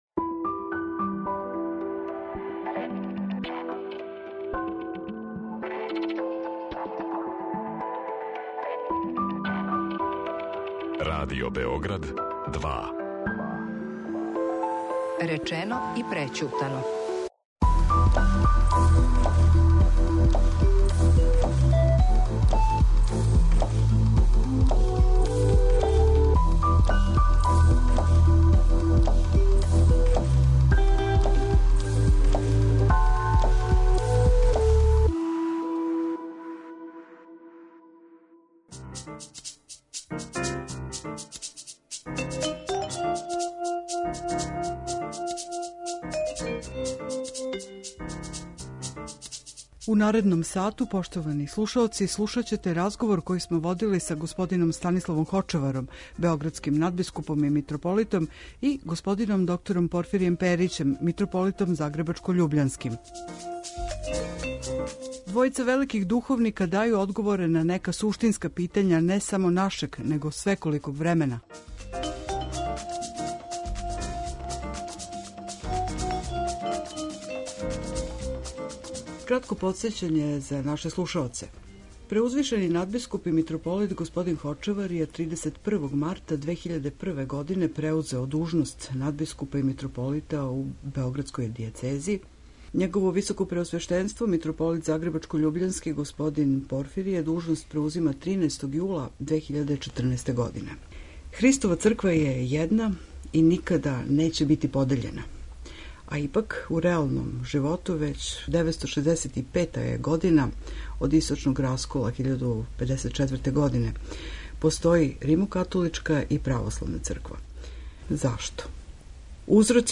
У данашњој емисији Речено и прећутано чућете први део разговора који смо поводом овогодишњих божићних и новогодишњих празника водили са београдским надбискупом и загребачко-љубљанским митрополитом.